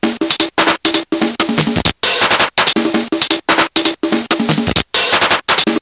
标签： C64 chiptunes 毛刺 厨房 lsdj 我的旋律 nanoloop 声音 今天
声道立体声